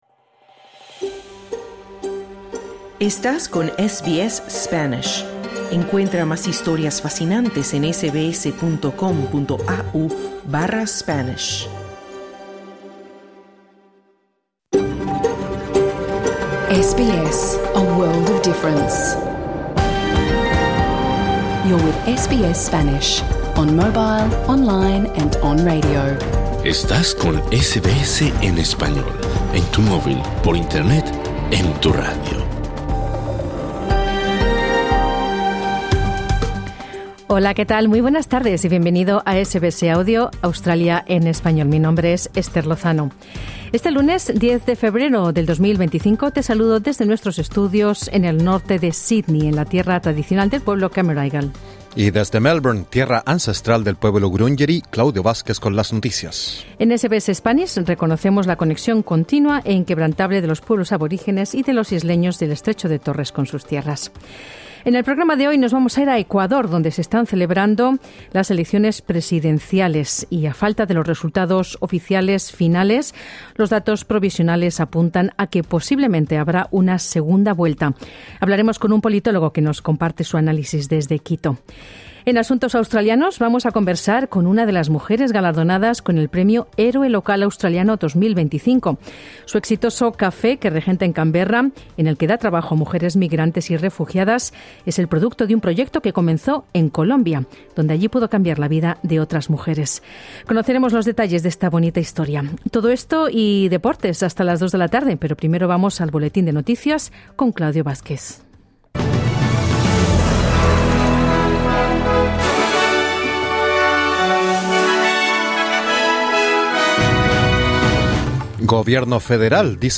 Programa en vivo | SBS Spanish | 10 febrero 2025